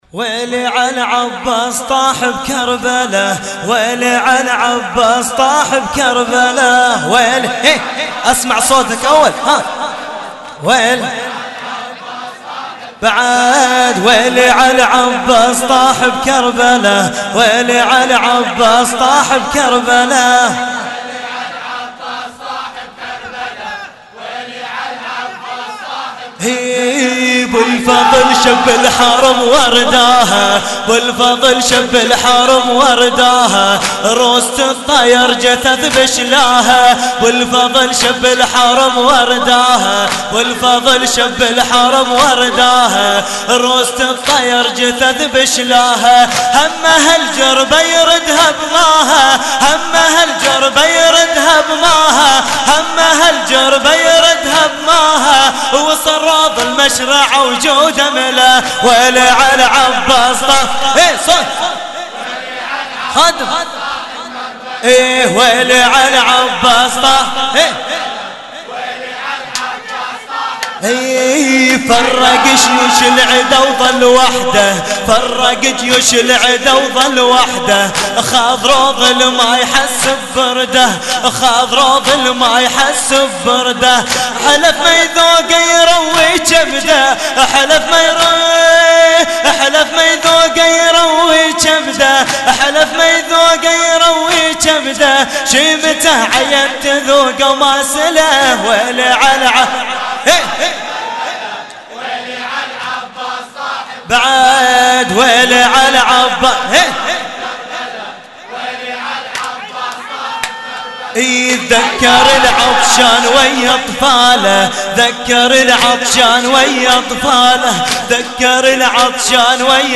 لطمية الليلـ 07 ـة الجزء (03)